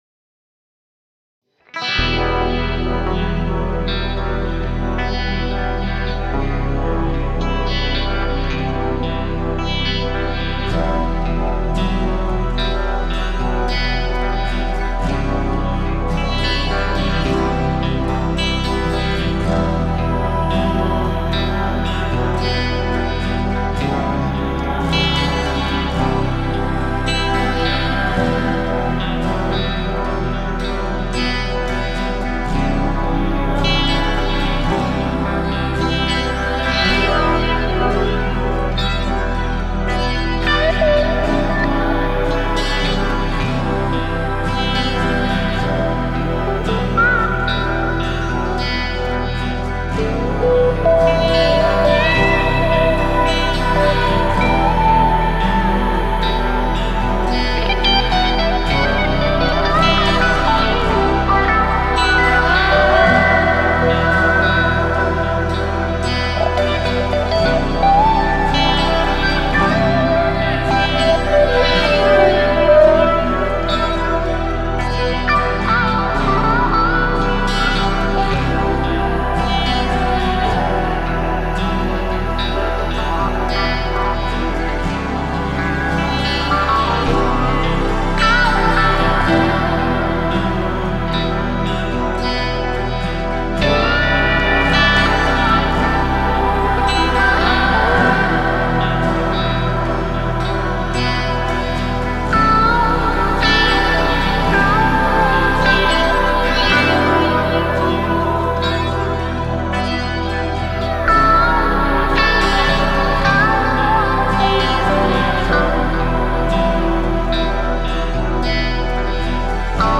"Building 89" - Dark blues instrumental
Dark, atmospheric blues track I recorded in quarantine.